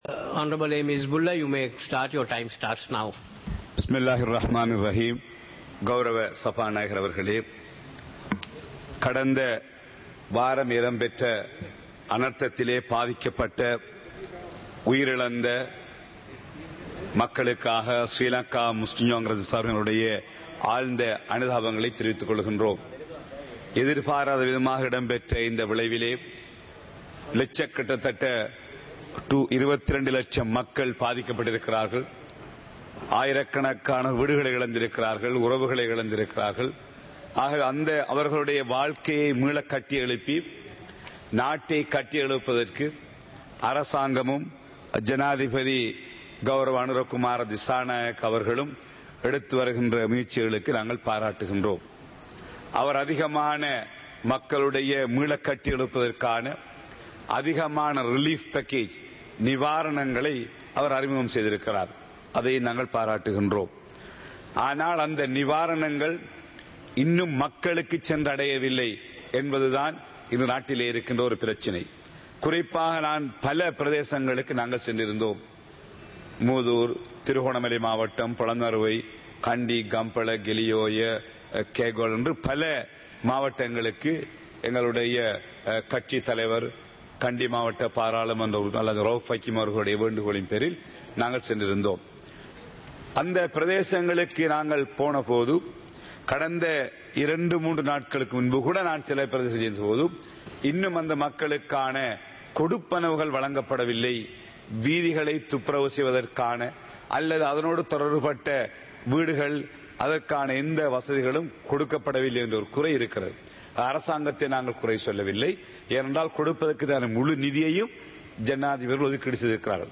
සභාවේ වැඩ කටයුතු (2025-12-18)